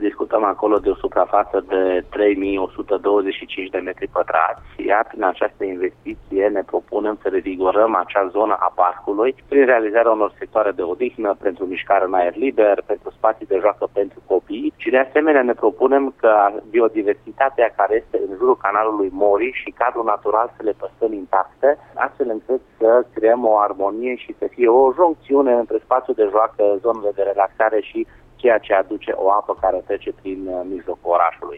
Invitat în acestă dimineață la Radio Cluj, viceprimarul Dan Tarcea: